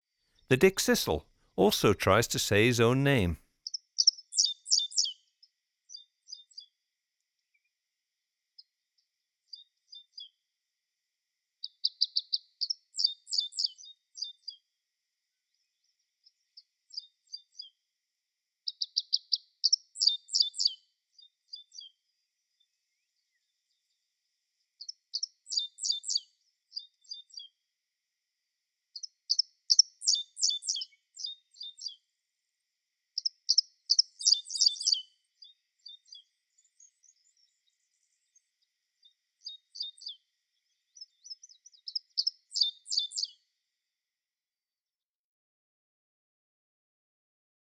Dickcissel’s Song
56-dickcissel.m4a